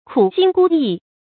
注音：ㄎㄨˇ ㄒㄧㄣ ㄍㄨ ㄧˋ
讀音讀法：